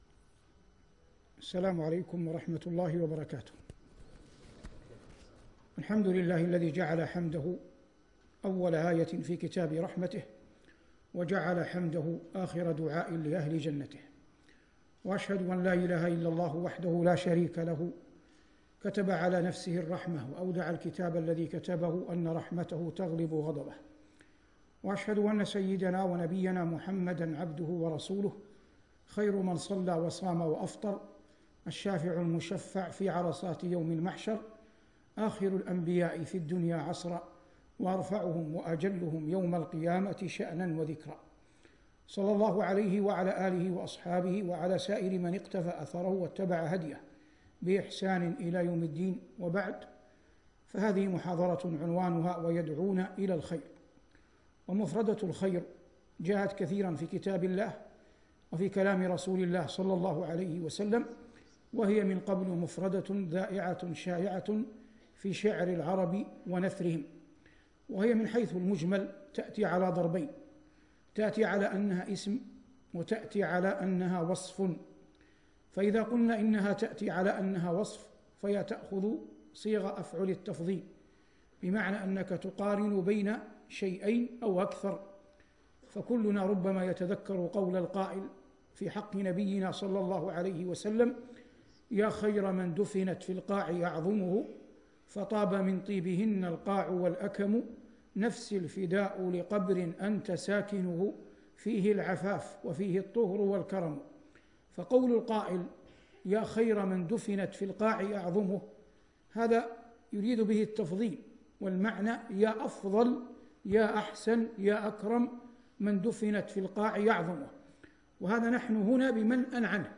محاضرة بعنوان: "يدعون إلى الخير" ضمن جائزة دبي للقرآن الكريم - رمضان 1438هـ